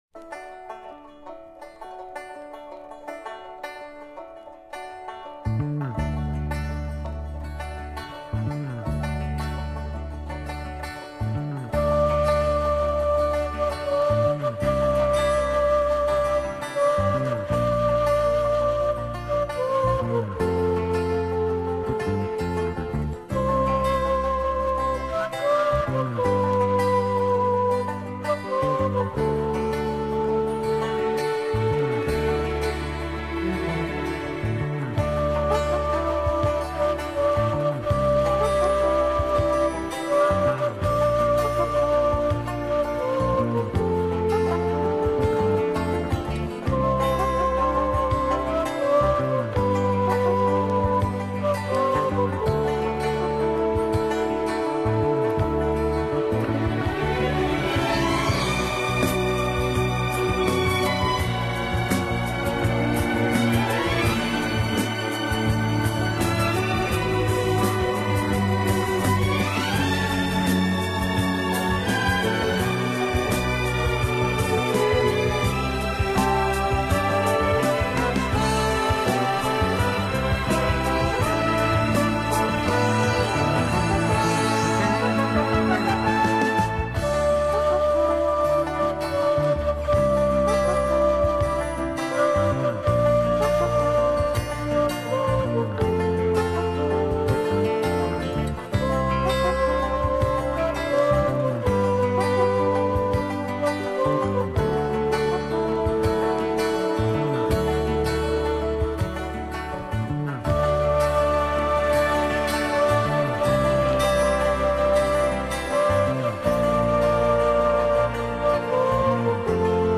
Ну и вторая...На пластинке инструменталка из фильма